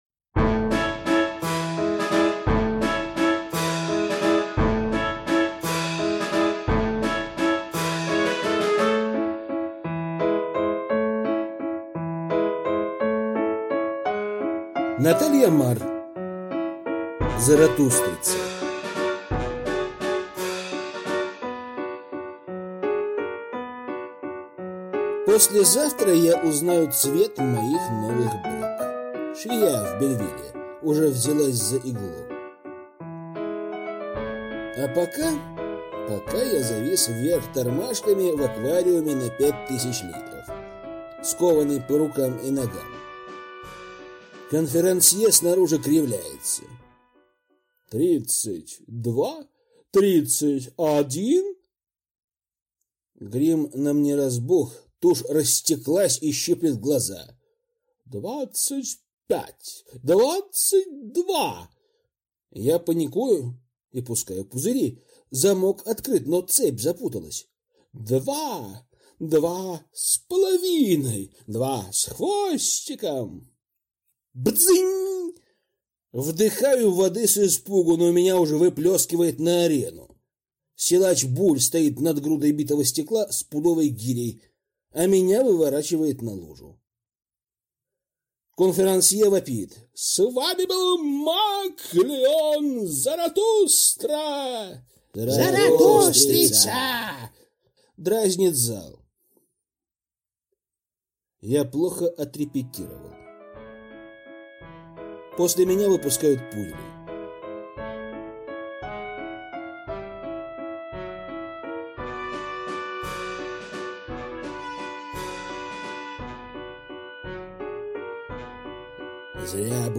Аудиокнига Заратустрица | Библиотека аудиокниг